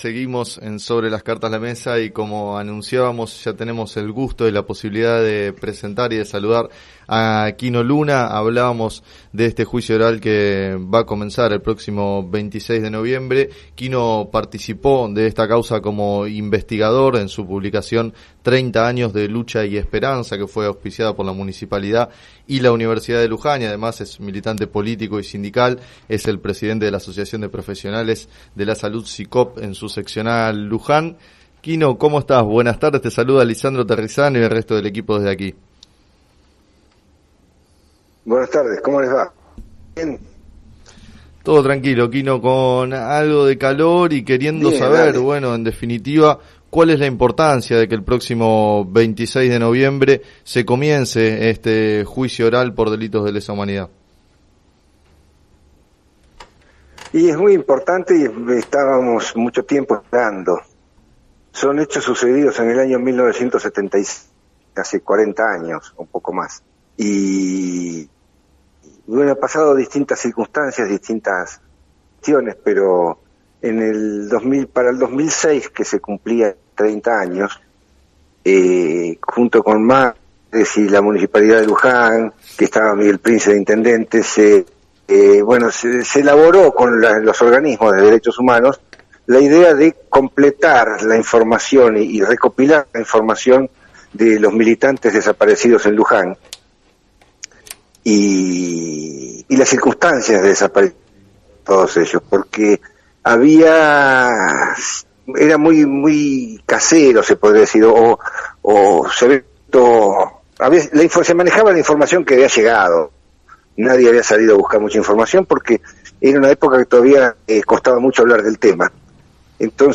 Entrevistado en el programa “Sobre las cartas la mesa” de FM Líder 97.7